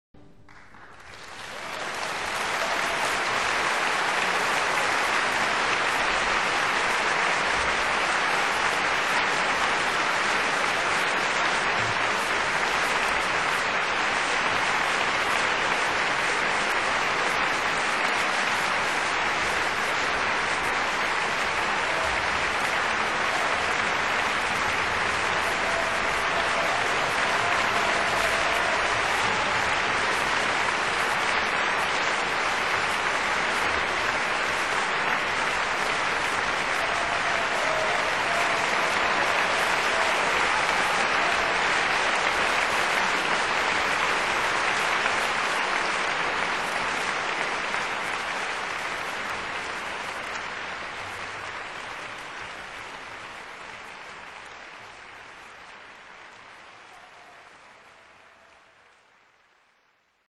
Long Applause